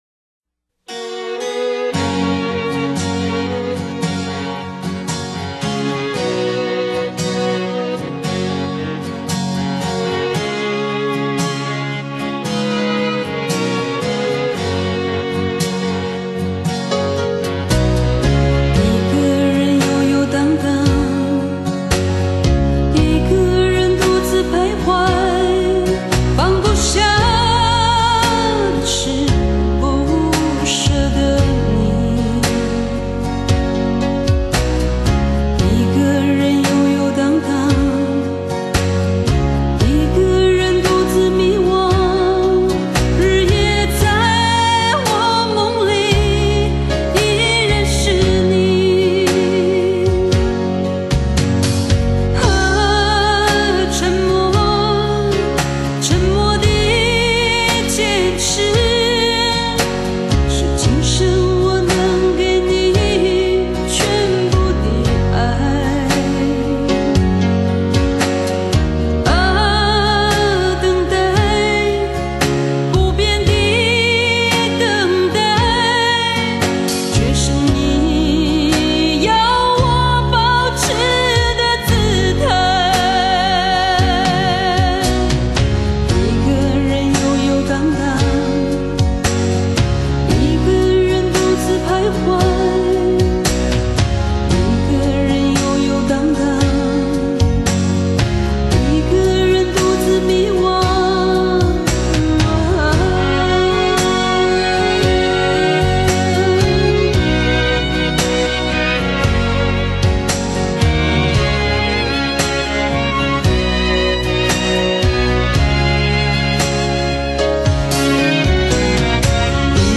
唱片音色说不上出色，